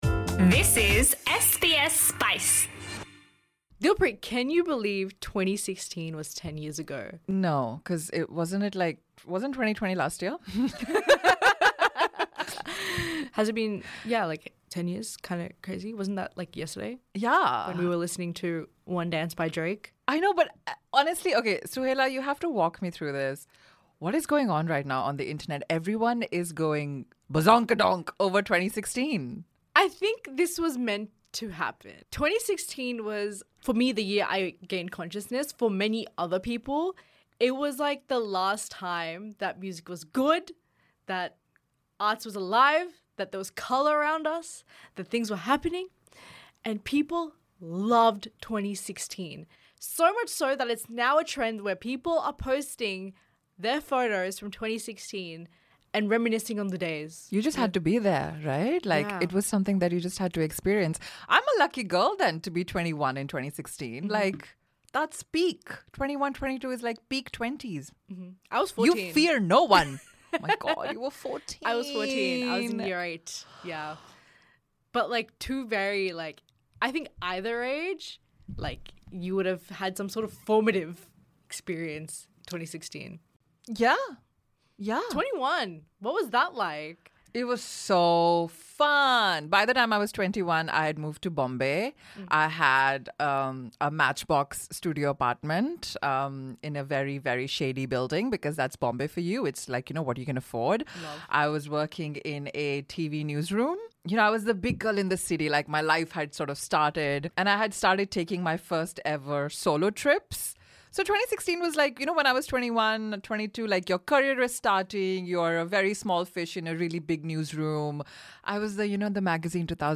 From the flower crown filter to fluorescent sneakers, we reminisce on the good, the bad and the spicy that defined a formative year for many Gen Z and Millennials. Get your eyebrows "on fleek" and listen to this "lit" conversation, only on SBS Spice.